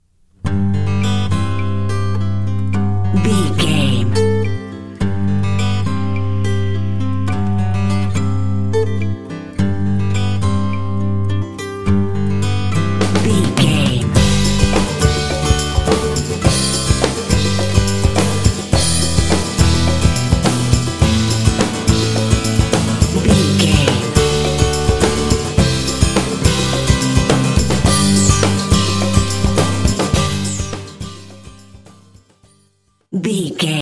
Uplifting
Ionian/Major
bouncy
groovy
bright
acoustic guitar
bass guitar
drums
pop
rock
contemporary underscore